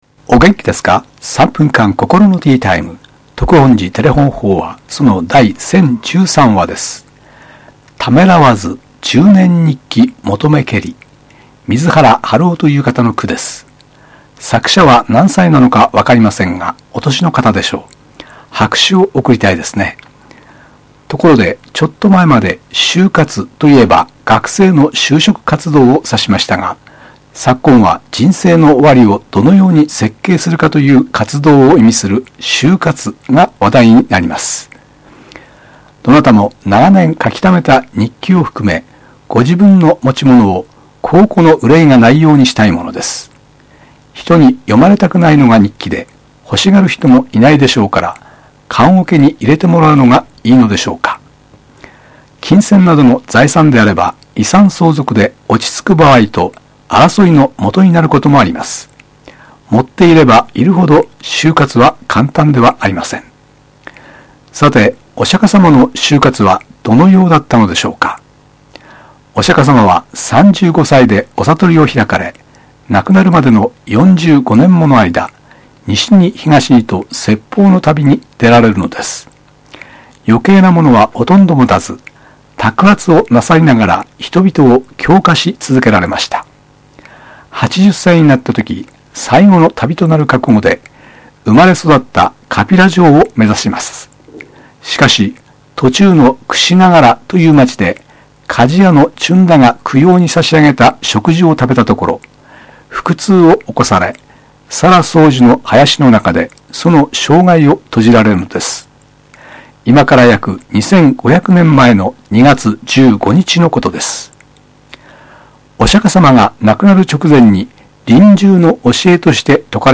テレホン法話
住職が語る法話を聴くことができます